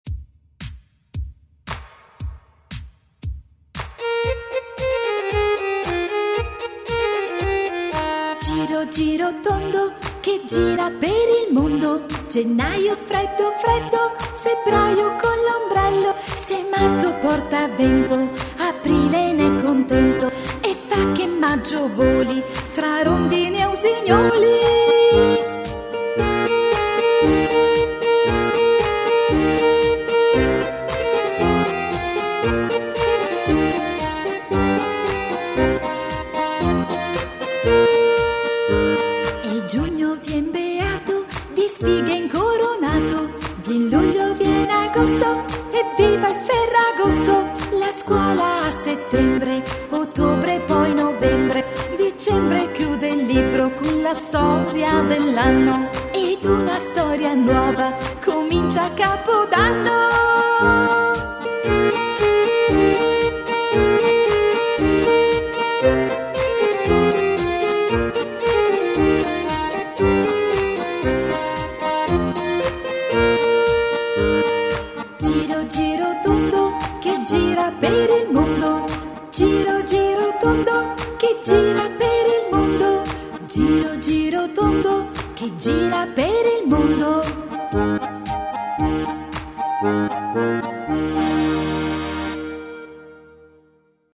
canzone